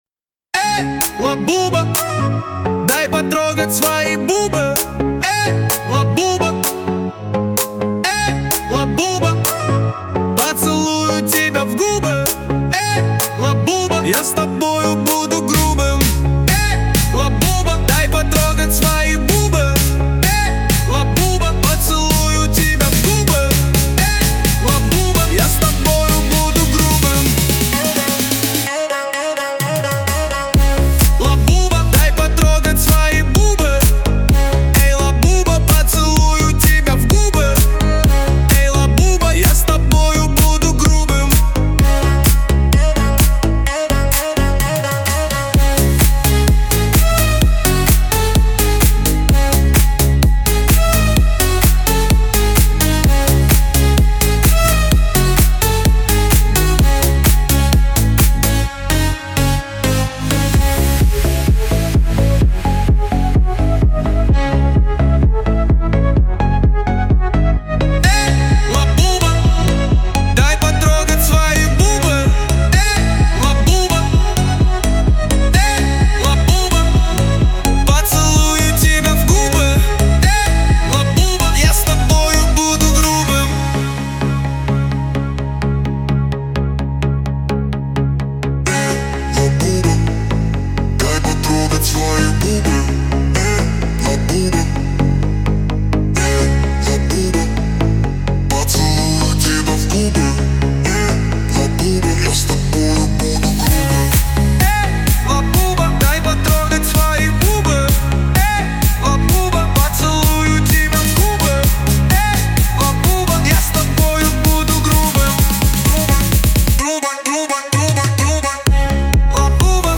Хаус